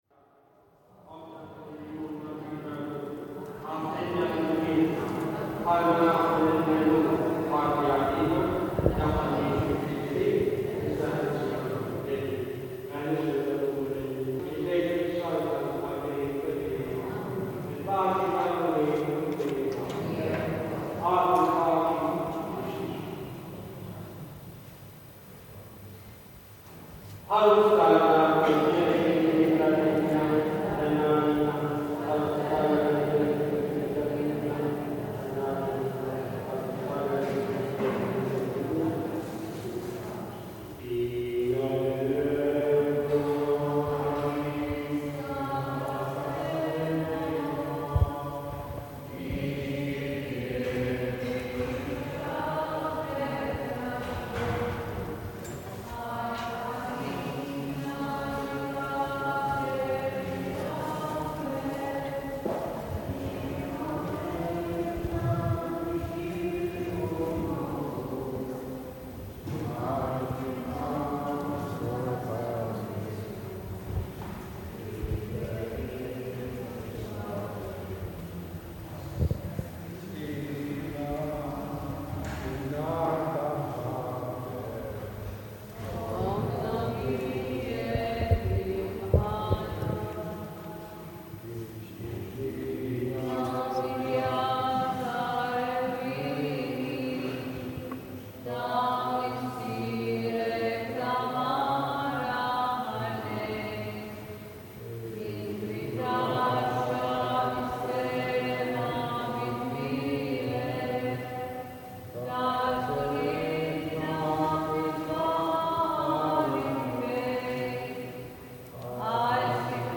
Midday mass
This is recording of a midday, midweek mass attended by a few elderly women in the cathedral in Victoria, Gozo, and is a sound that is deeply embedded in my memory of place and immediately connects me to the experience of growing up in Malta.